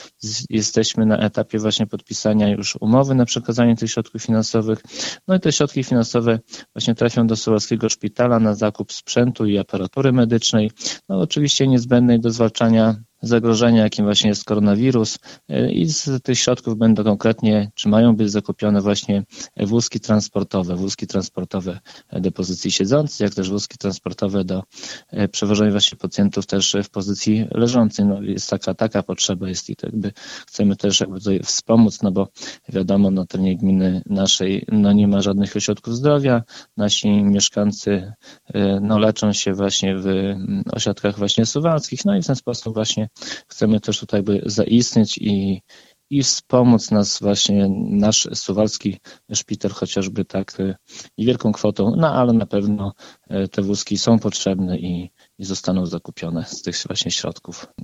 Niedawno, o wsparciu placówki kwotą 20 tysięcy złotych, zdecydowały władze gminy Suwałki. O szczegółach mówi Zbigniew Mackiewicz, wójt gminy.